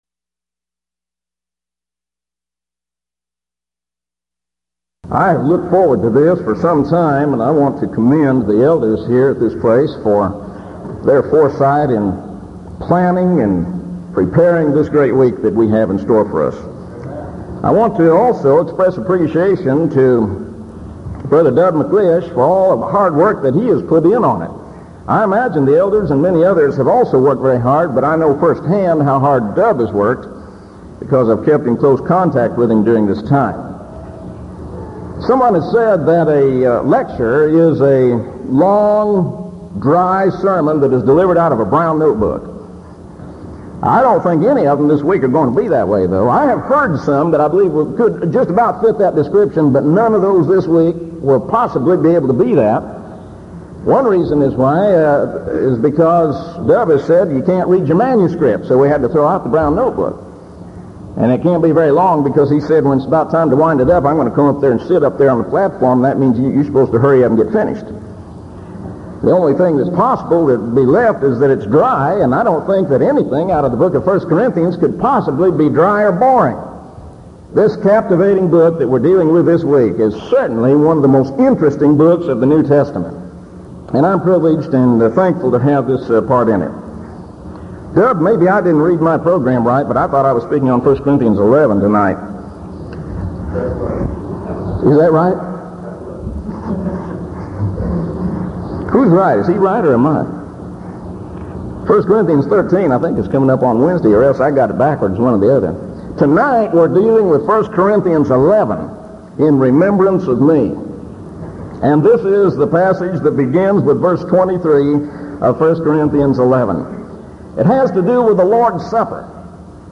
this lecture